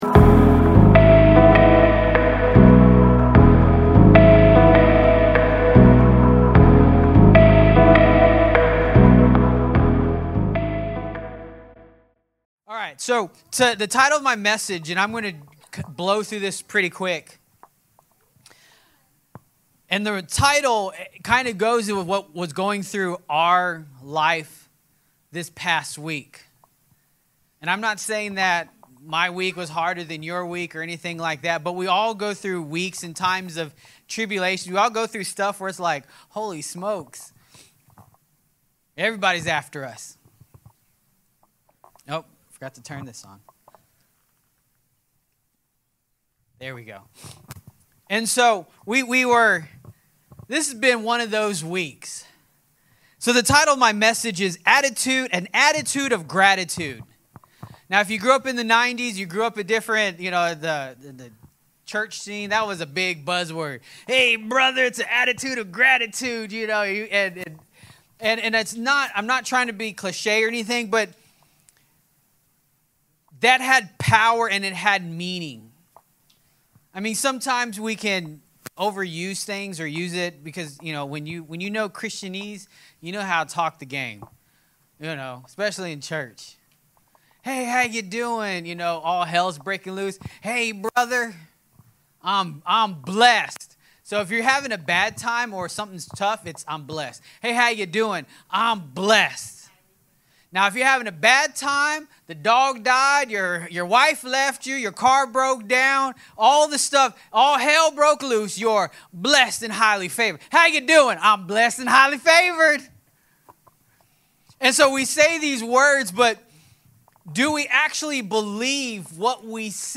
Sermons | Forerunner Church